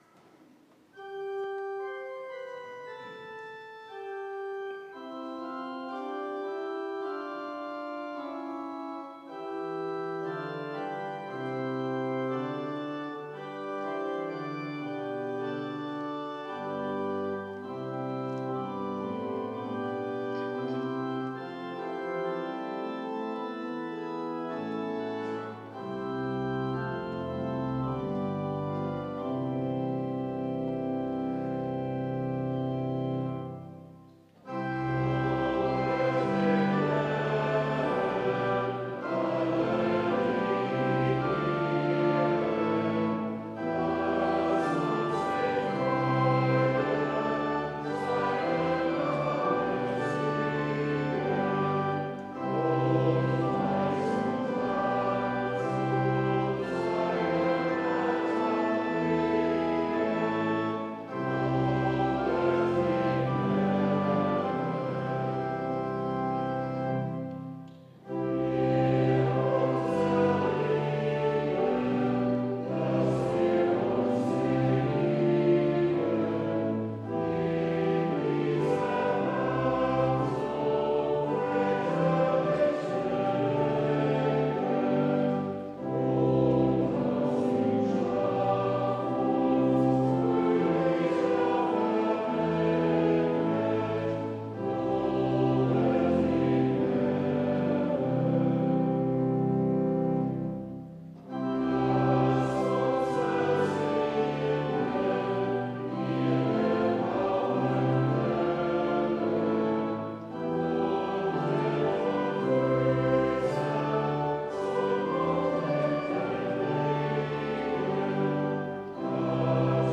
Lobet den Herrn alle, die ihn ehren... (LG 420,1-6) Evangelisch-Lutherische St. Johannesgemeinde Zwickau-Planitz
Audiomitschnitt unseres Gottesdienstes am 8. Sonntag nach Trinitatis 2023